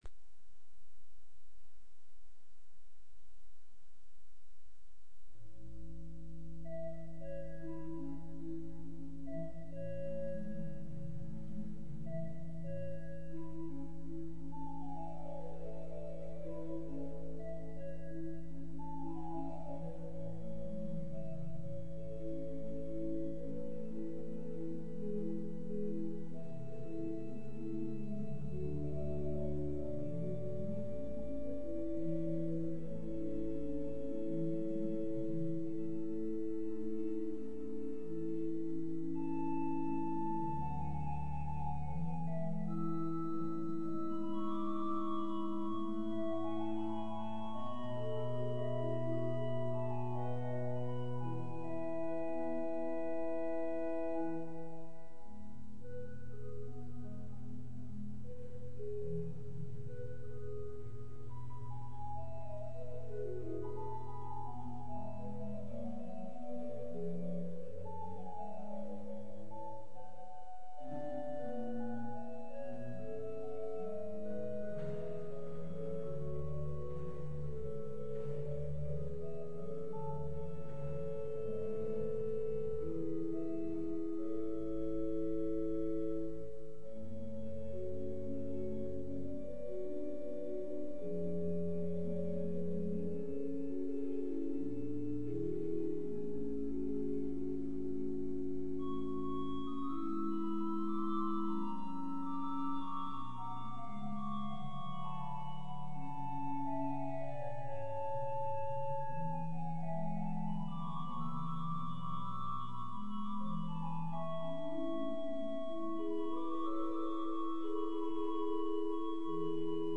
Villach, Evangelische Kirche im Stadtpark – Reil 1994, II/18
Es ist in Klang und Disposition an Vorbildern des barocken niederländischen Orgelbaus orientiert.
Unter den Registern sind die Quintadeen 8 voet – ein zartes Register mit der Duodezim als besonders farbigem Oberton – und die Zungenstimmen in allen drei Werken besonders hervorzuheben.